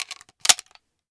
Reloading_begin0006.ogg